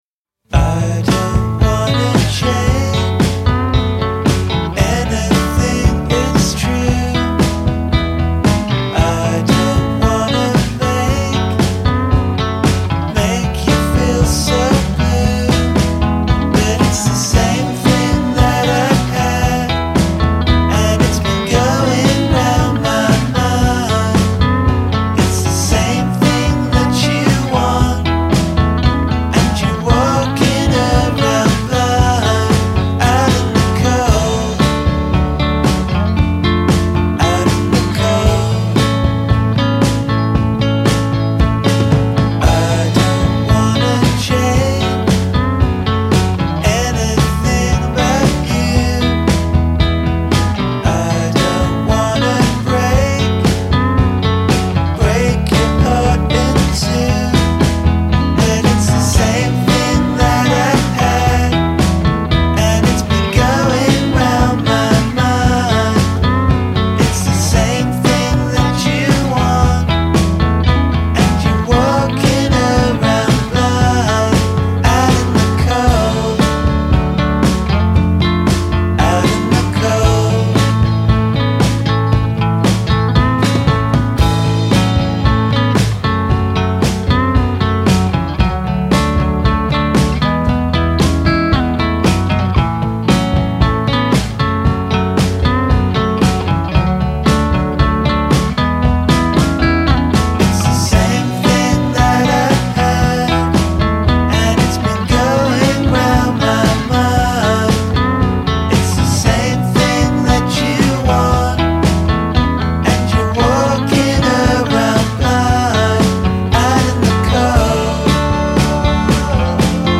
onesto e rilassato